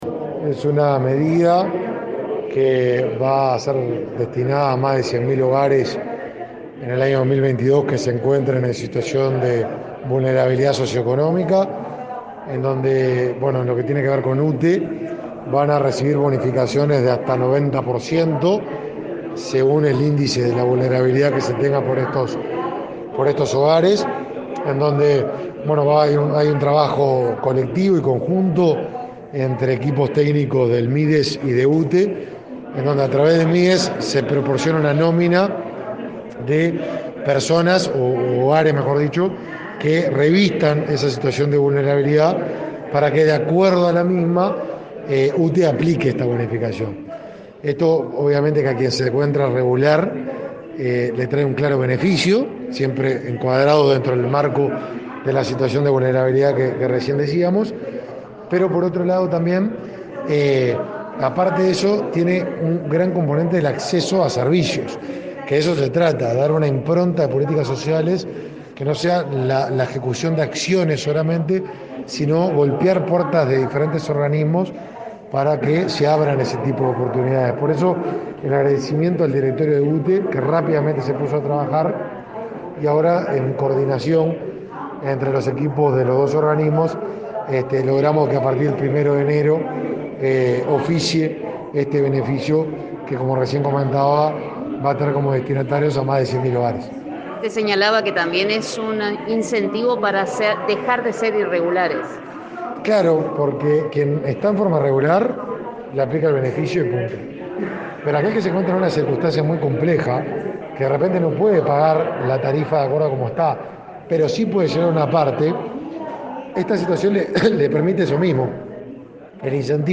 Entrevista al ministro de Desarrollo Social, Martín Lema